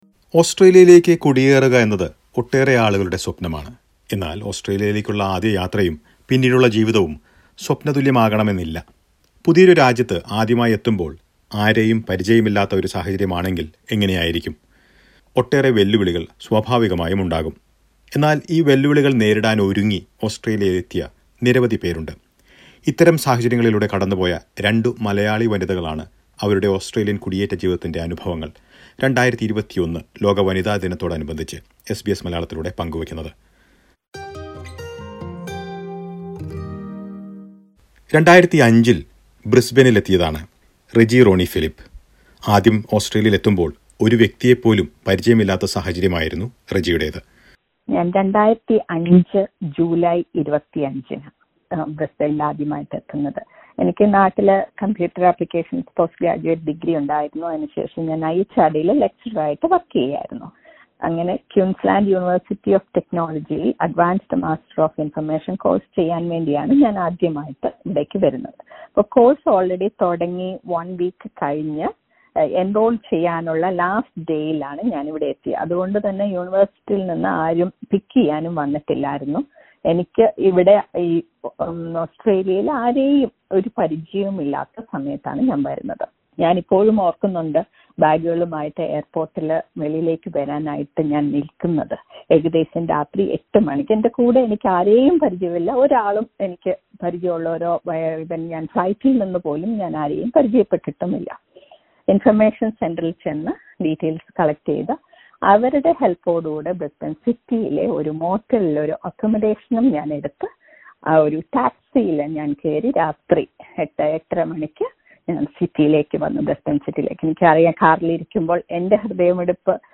Malayalee women who landed in Australia without knowing anyone here share their experiences on this Women's Day.